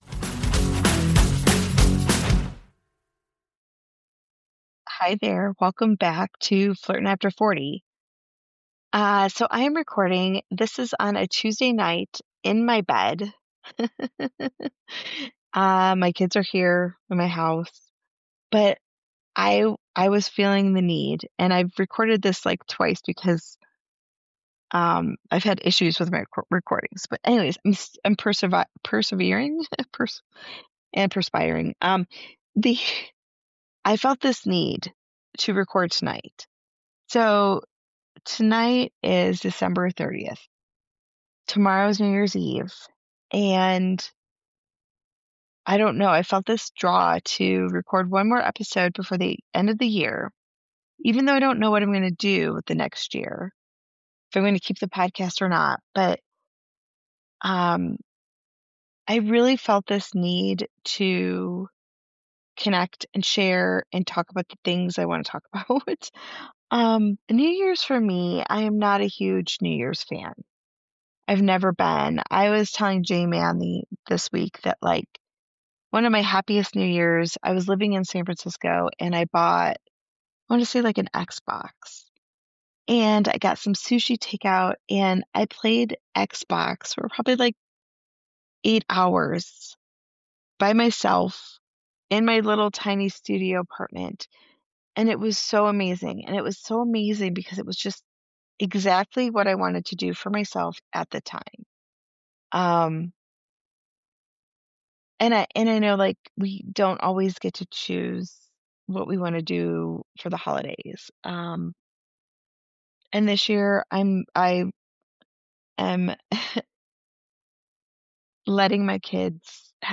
This is a quiet reflection on growth, trust, and entering the new year open instead of braced.